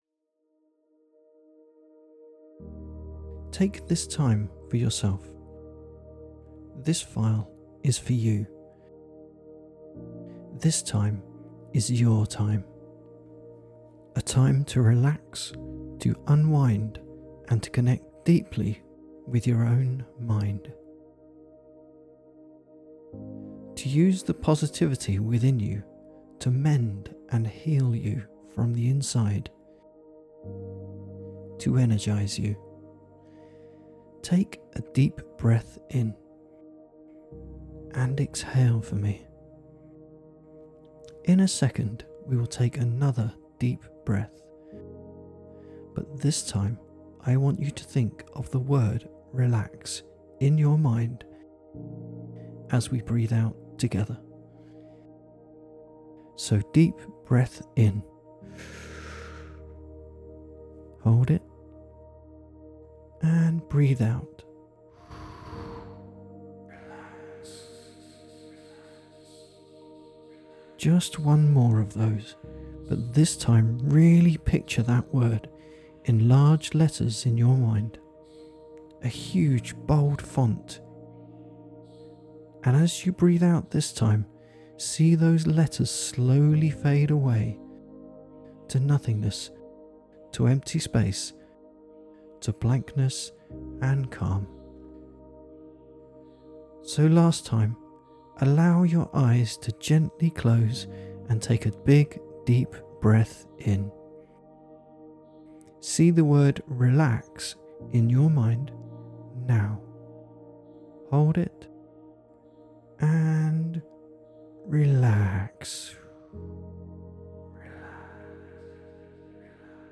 I calmly talk you down into a relaxing deep trance. Get you to repeat some phrases after me.